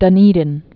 (dŭn-ēdn)